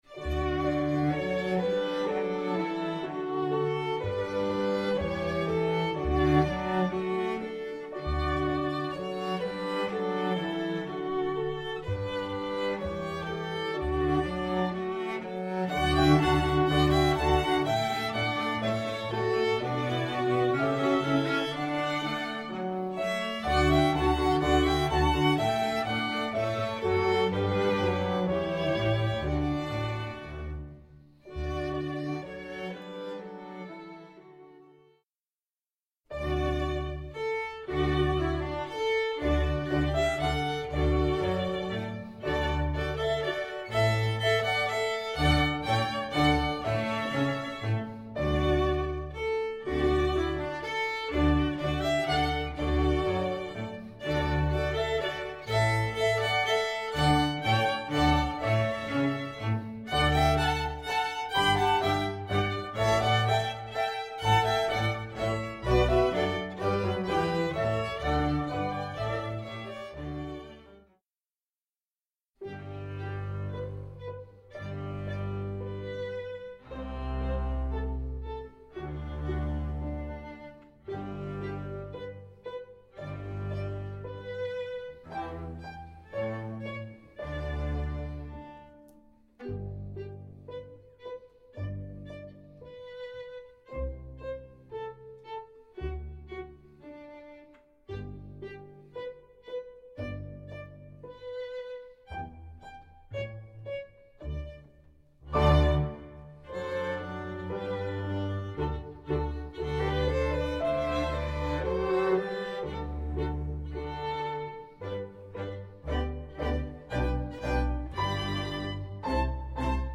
Orchestre à Cordes